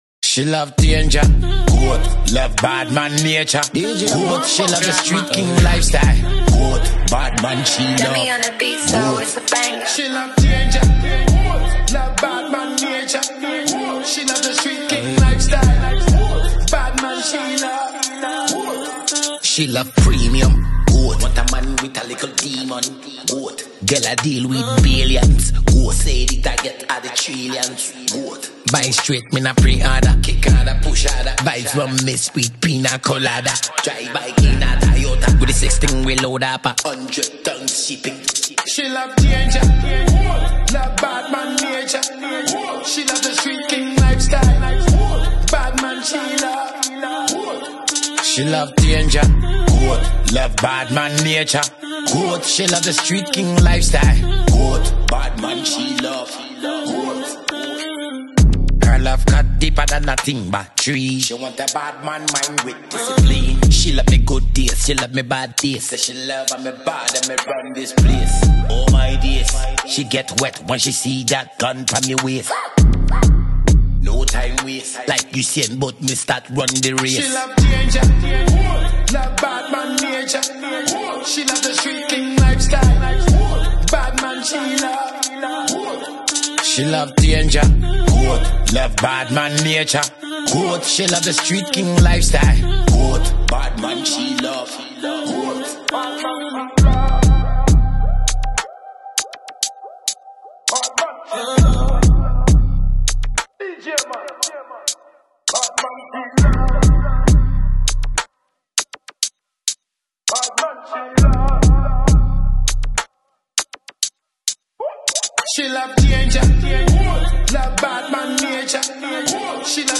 afrobeats dancehall